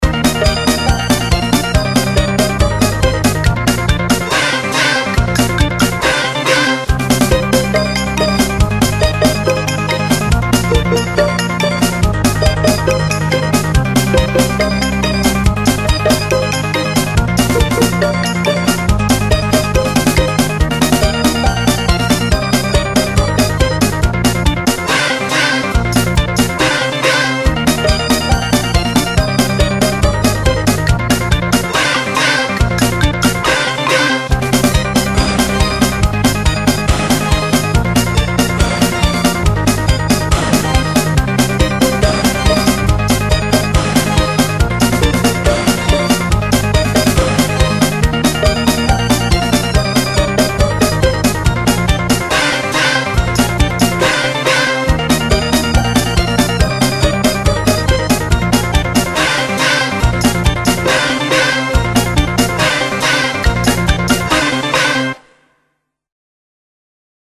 • Качество: 320, Stereo
смешные
прикольная музыка